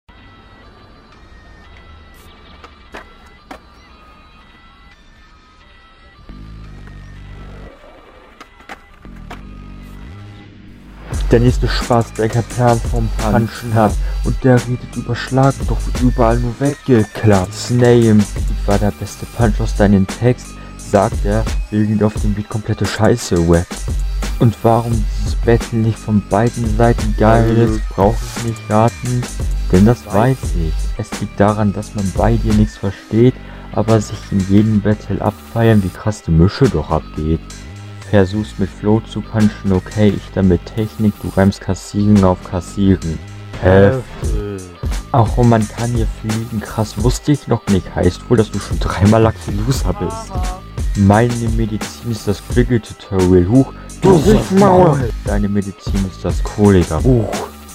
Rückrunde 1
Da ist einfach überhaupt kein Flow vorhanden.